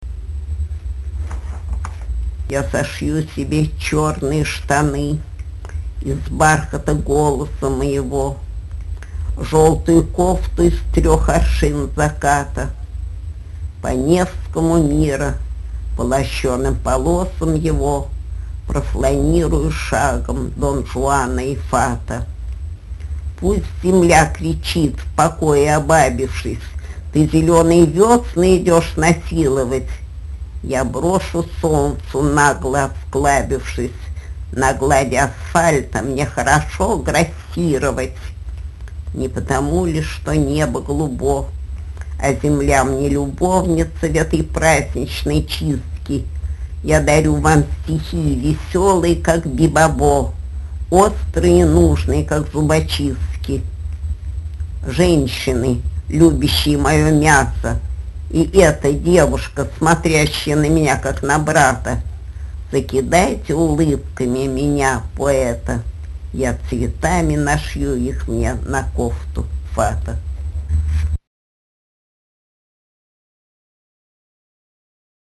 10. «Владимир Маяковский – Кофта фата (Читает Лиля Брик)» /